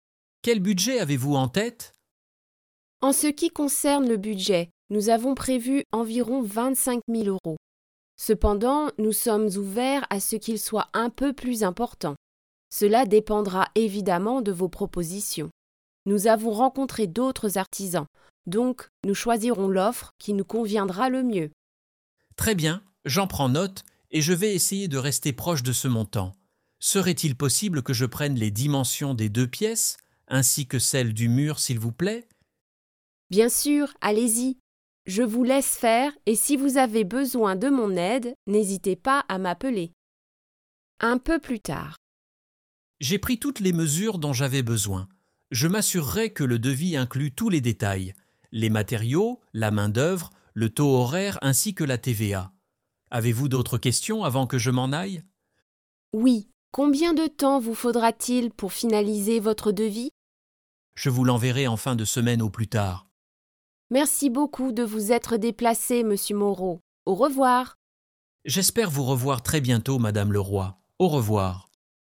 Madame Leroy discusses her plans for home renovations with her prospective contractor Monsieur Moreau in this rich dialogue full of great French construction vocabulary and expressions.